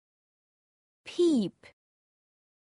Amazon AWS (pronunciation):